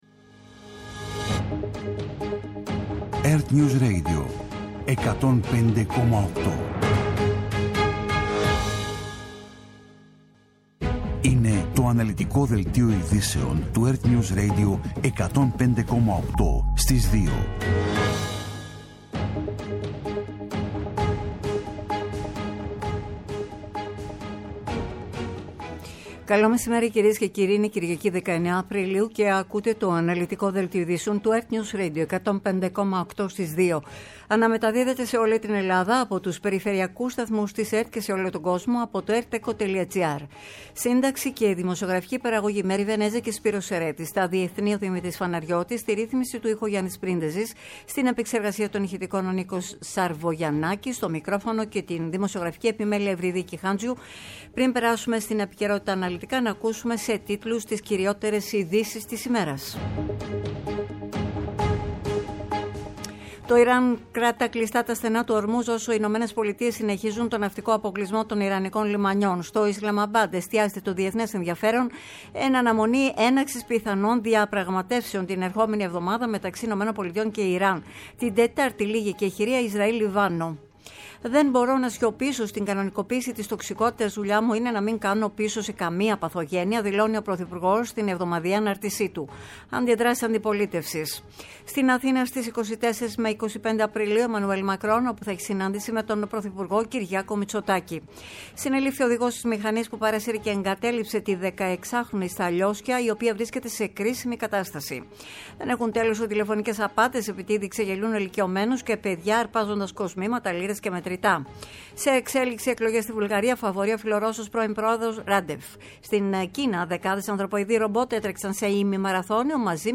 Το κεντρικό ενημερωτικό μαγκαζίνο στις 14.00.
Με το μεγαλύτερο δίκτυο ανταποκριτών σε όλη τη χώρα, αναλυτικά ρεπορτάζ και συνεντεύξεις επικαιρότητας.